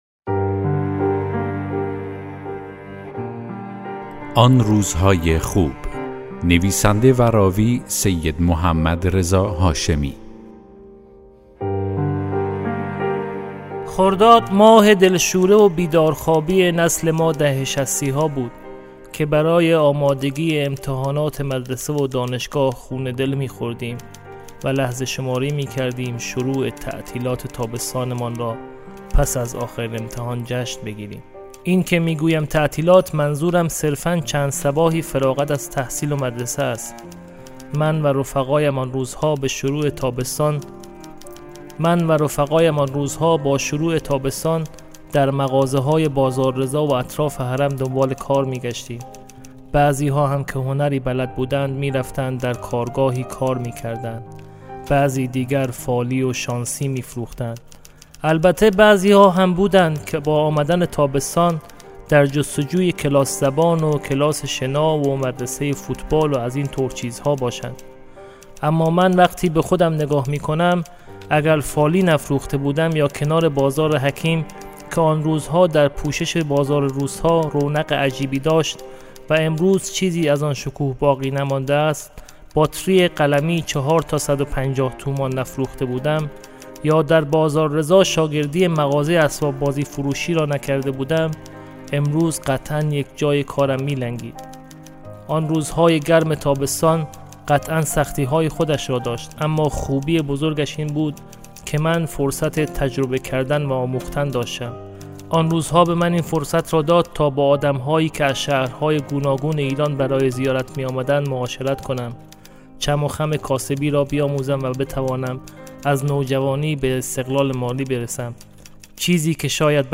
داستان صوتی: آن روز‌های خوب